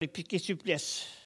Localisation Soullans ( Plus d'informations sur Wikipedia ) Vendée
Catégorie Locution